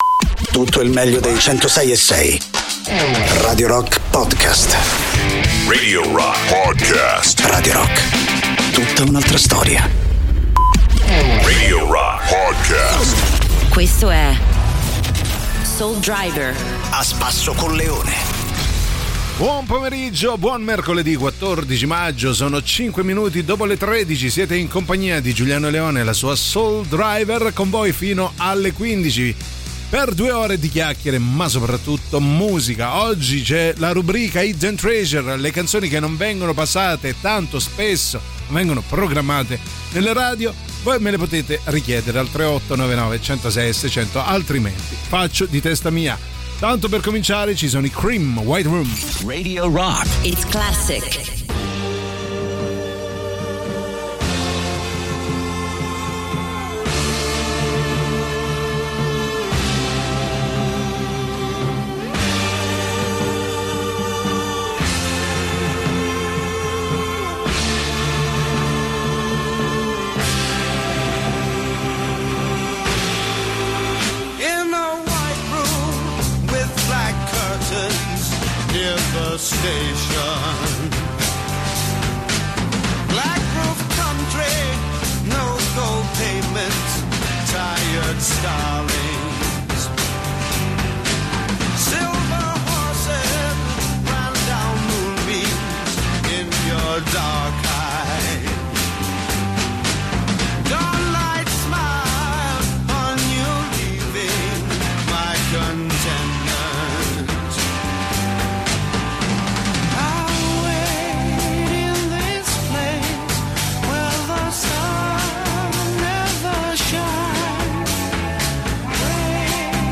in diretta dal lunedì al venerdì, dalle 13 alle 15, con “Soul Driver” sui 106.6 di Radio Rock.